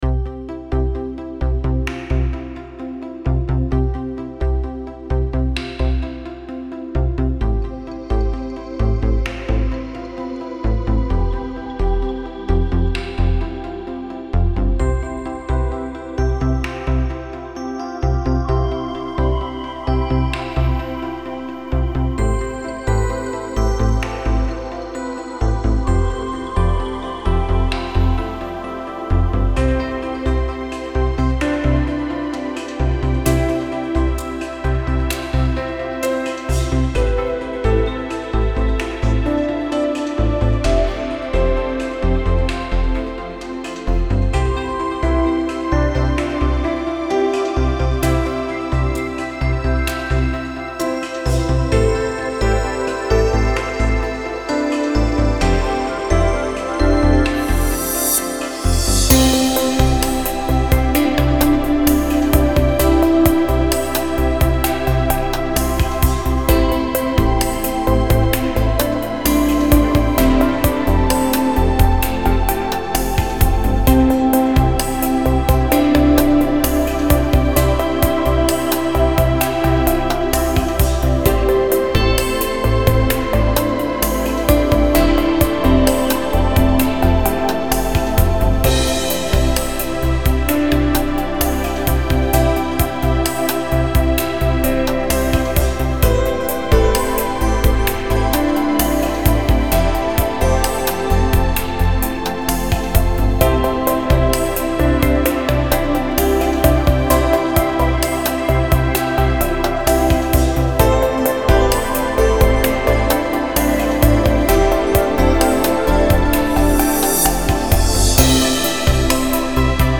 چیل‌اوت ریتمیک آرام موسیقی بی کلام